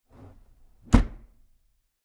Звуки посудомоечной машины
Звук захлопывающейся дверцы посудомойки